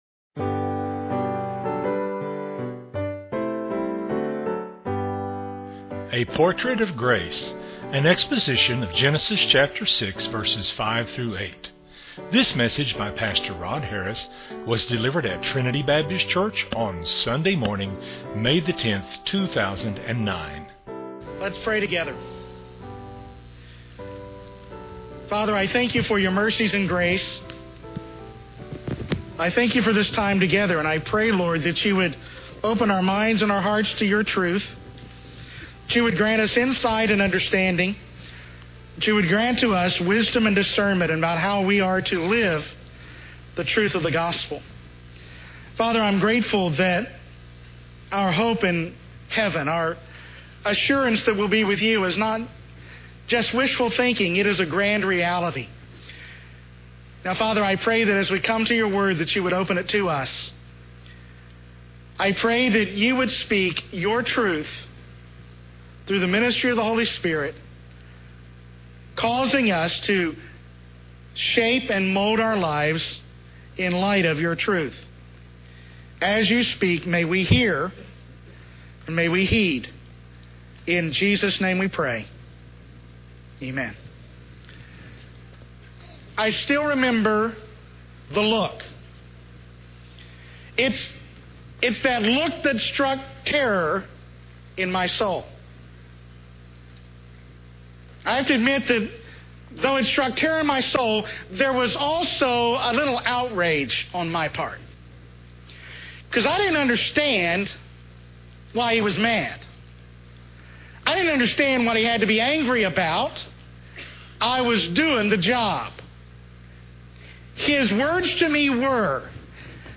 was delivered at Trinity Baptist Church on Sunday morning